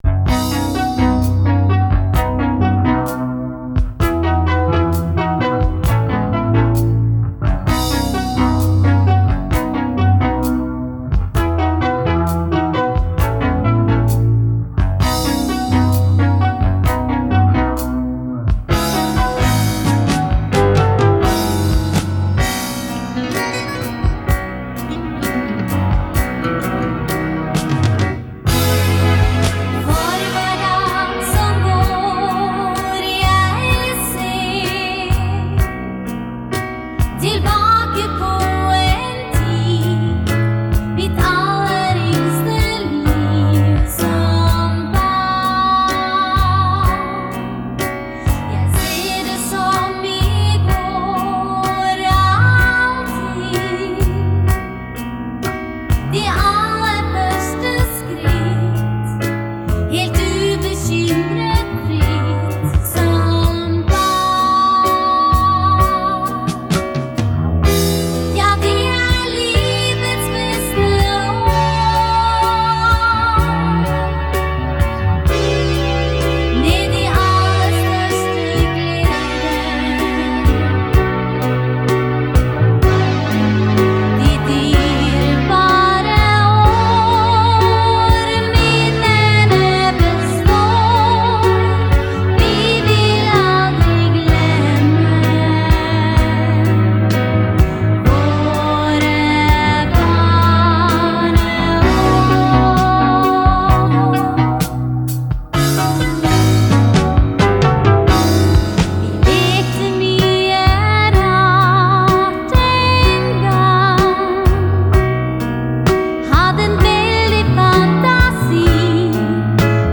Innspilt i BEL Studio, Oslo og på diverse andre steder.